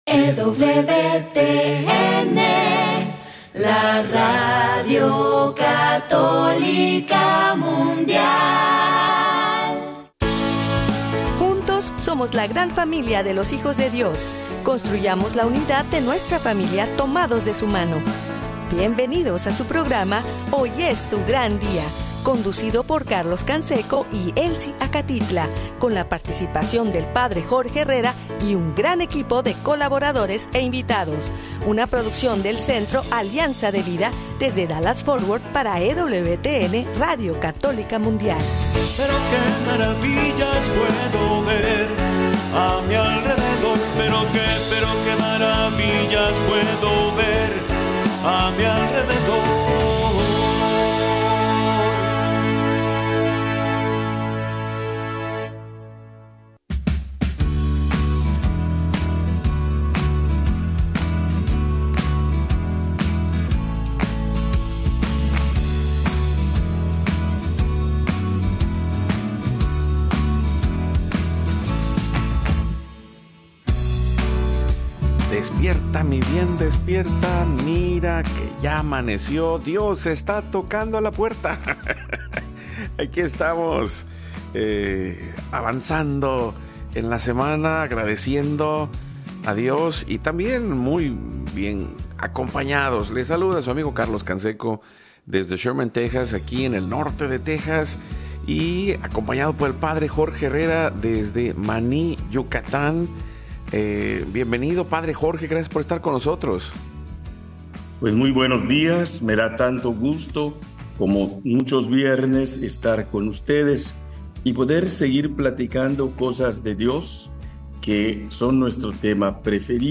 1 La paz de manoa de Maria 55:20 Play Pause 3d ago 55:20 Play Pause Play later Play later Lists Like Liked 55:20 Hoy es tu gran día es un programa de evangelización en vivo desde Dallas, Texas, para vivir en plenitud la vida en Cristo, caminando junto a El para ver las maravillas a nuestro alrededor en la vida diaria como discípulos.